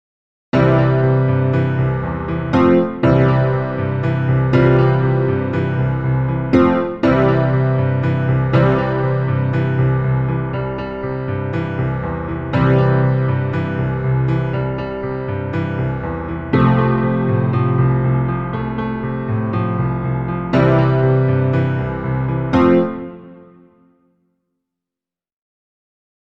Zunächst ist das Ausganspattern zu hören, danach dann die Variationen, welche mit ChordPotion erzeugt wurden.
Piano:
chordpotion-piano-1.mp3